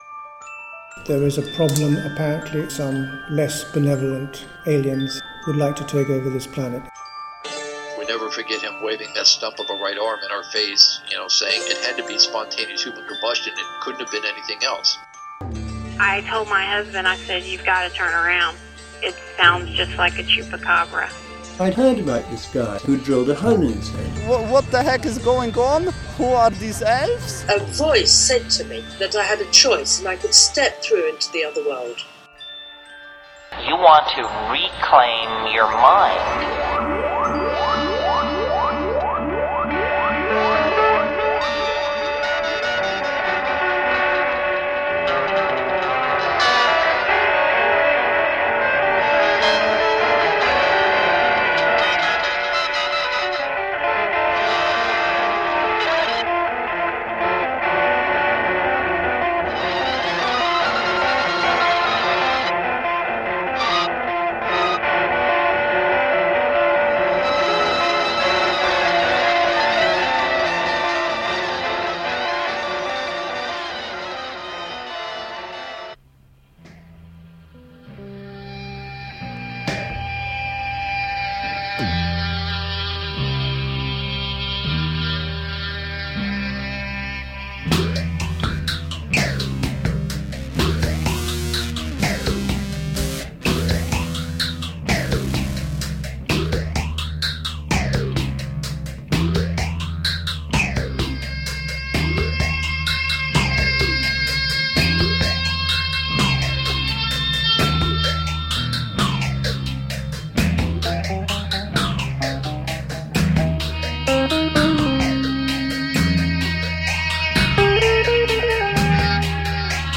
Fantastic interview with Helios Creed here
Helios is amicable, humorous, and seems to be enjoying himself discussing his music.